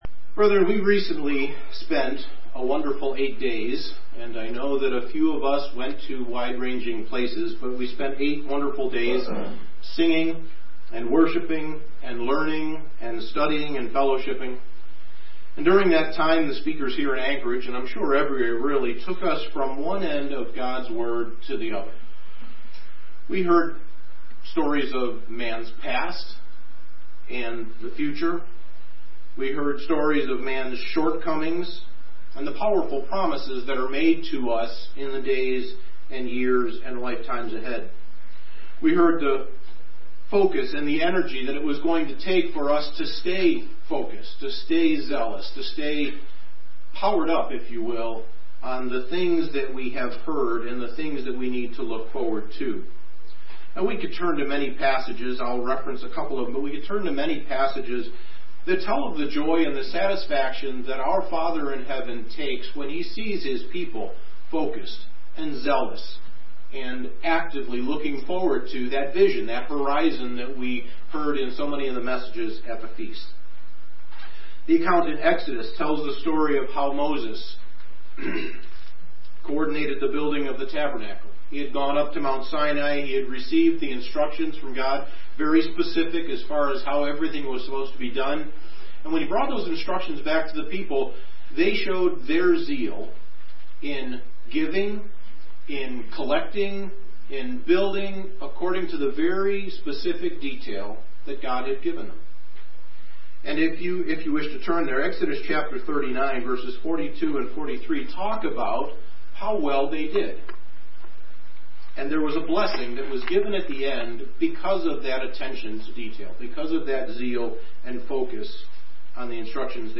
Sermons
Given in Anchorage, AK Soldotna, AK